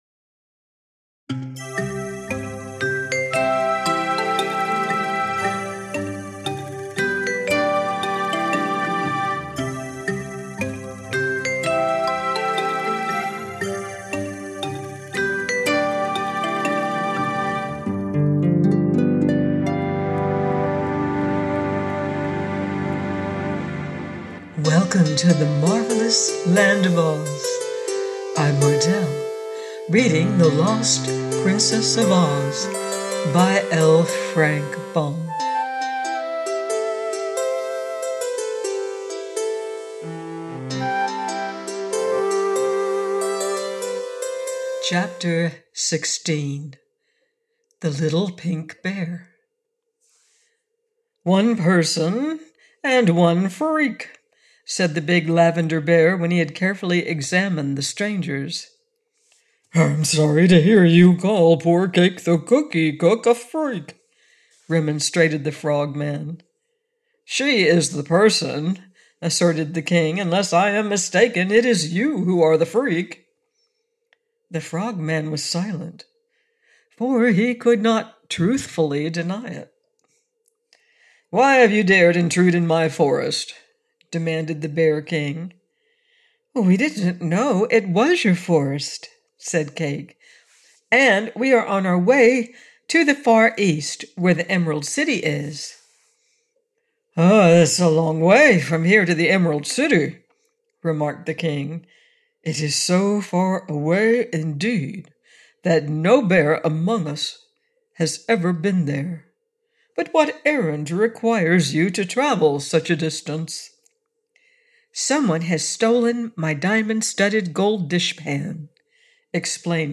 The Lost Princess of Oz by Frank L. Baum - AUDIOBOOK